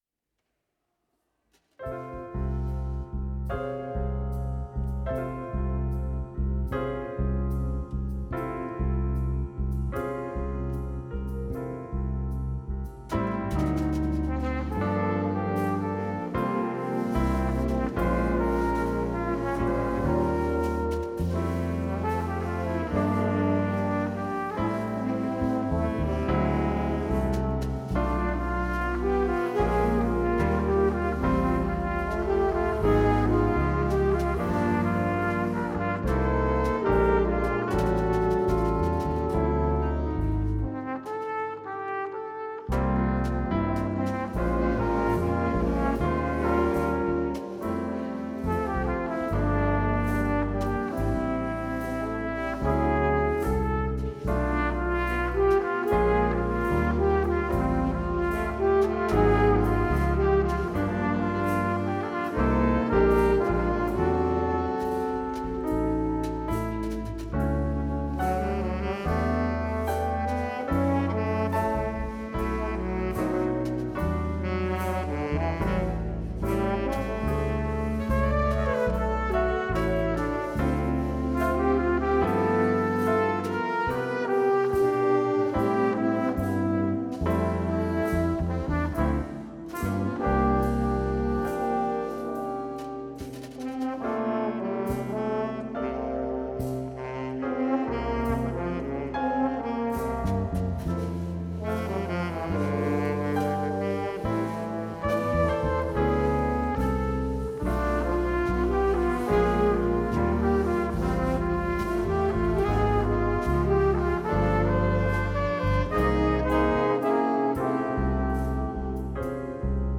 Weihnachtskonzert 2021
Immerhin konnten die einzelnen Stücke in der Gebhardskirche aufgenommen werden und stehen hier für Sie bereit.
SuJazzSo Jazz-Combo
Trompete
Altsaxophon
Tenorsaxophon
Tenorposaune
Klavier
Schlagzeug